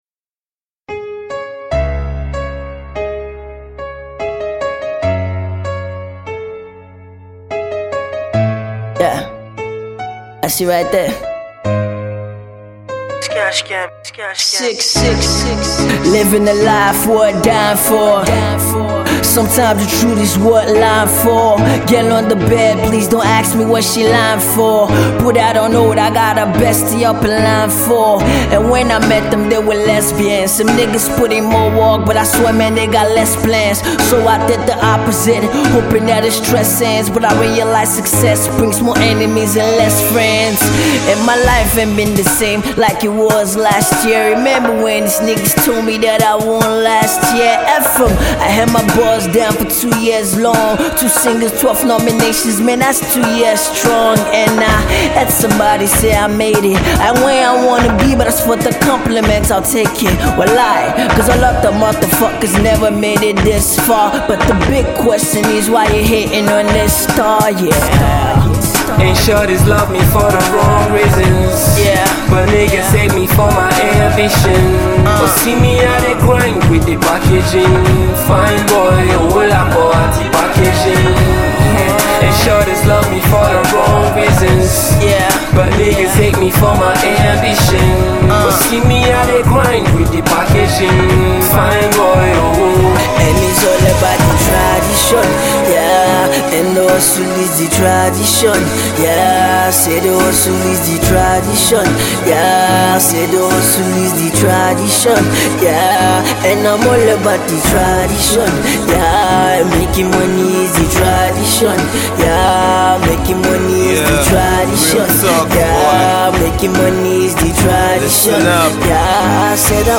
Afro Hip-Hip